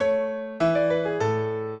minuet3-3.wav